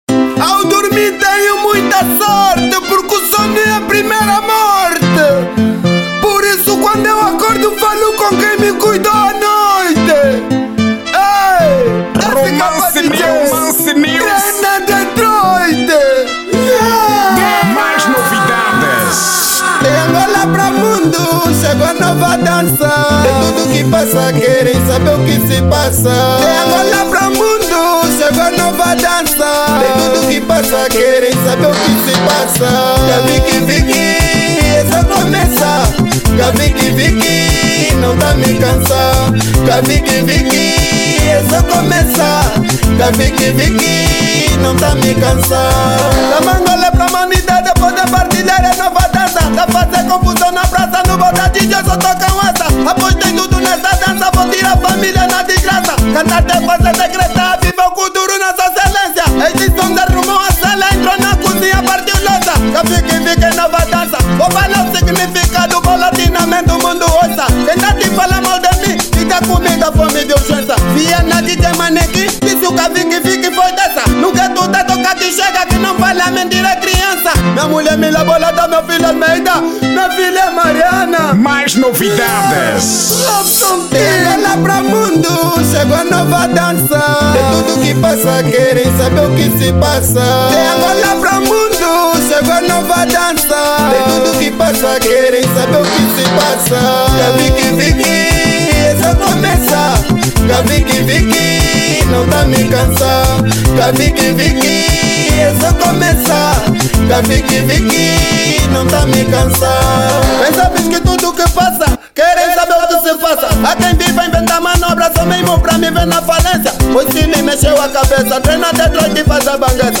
Estilo: Kuduro